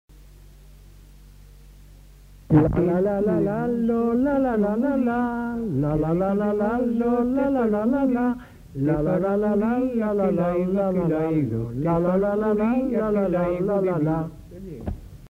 Aire culturelle : Haut-Agenais
Genre : chant
Effectif : 2
Type de voix : voix d'homme
Production du son : fredonné ; chanté
Danse : scottish